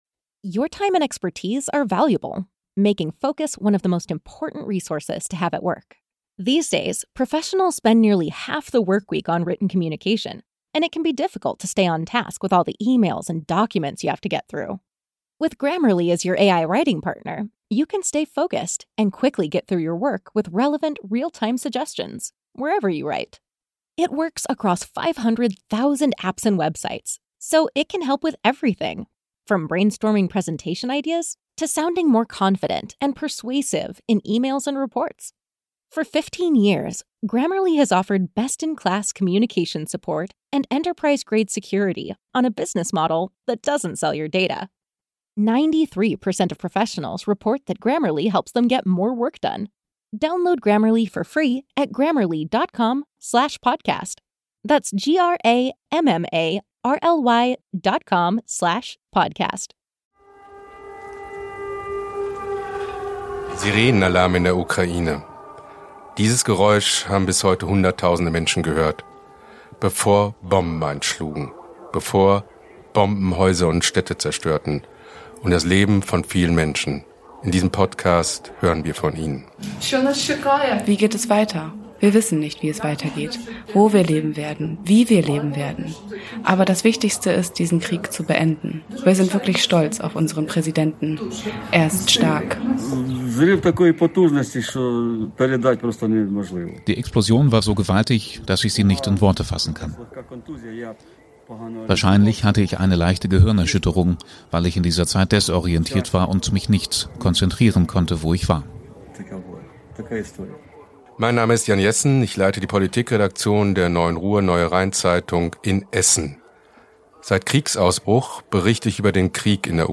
Im Podcast erzählen Betroffene ihre Geschichten.